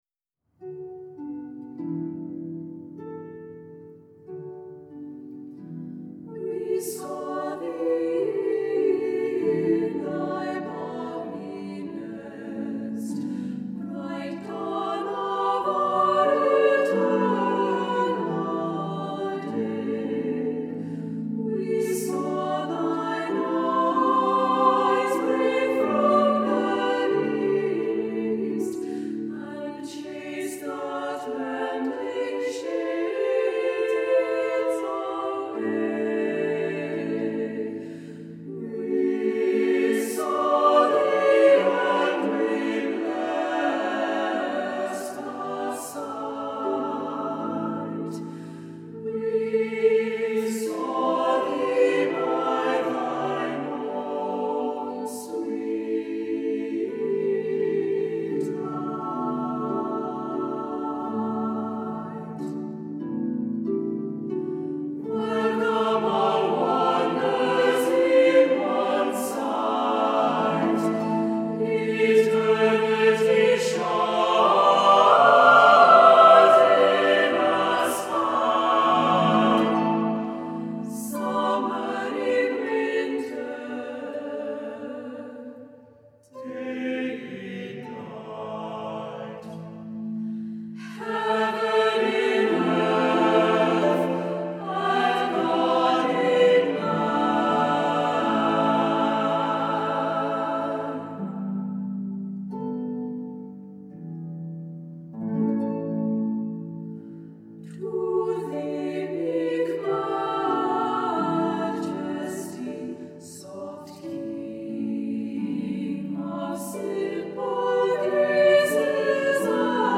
Accompaniment:      None
Music Category:      Choral